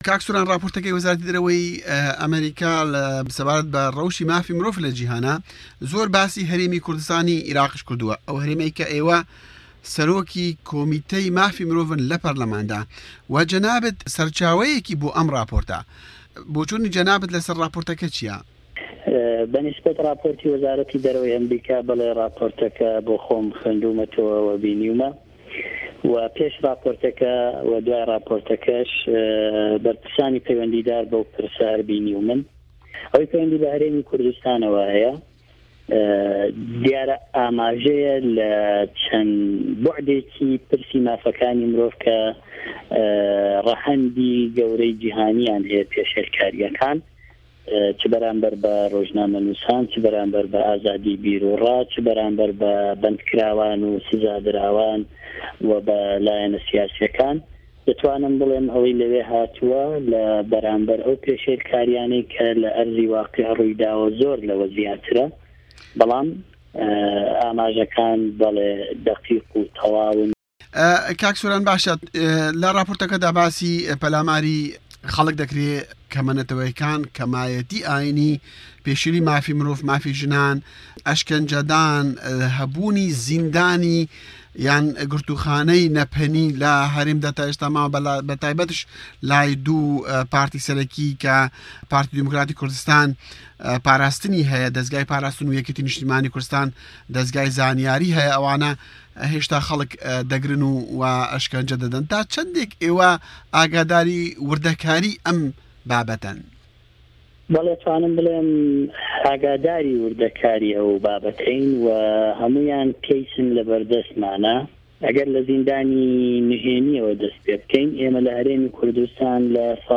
وتووێژی سۆران عومەر